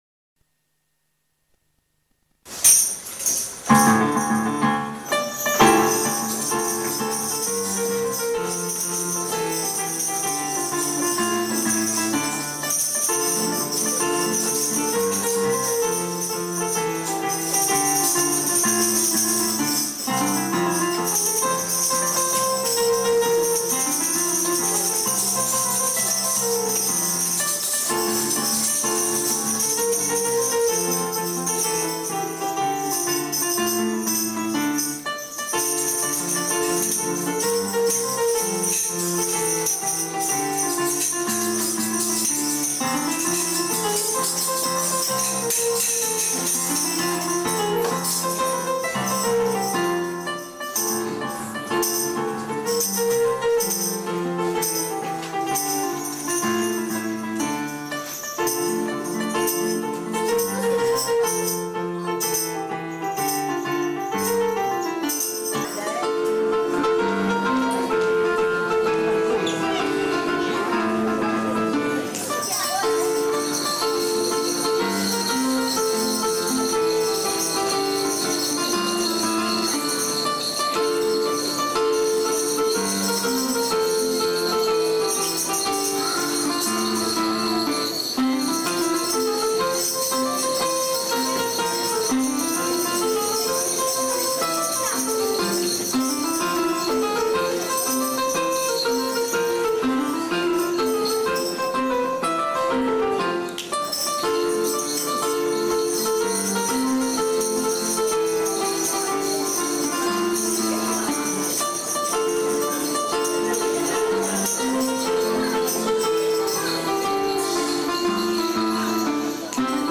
Description - TARANTELLA (musique et danse traditionnelle de Campania)